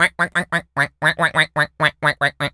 duck_quack_seq_long_04.wav